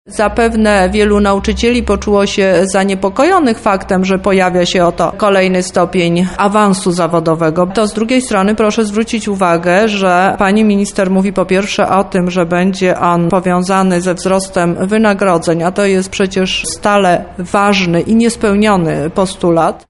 – mówi Teresa Misiuk, Lubelski Kurator Oświaty.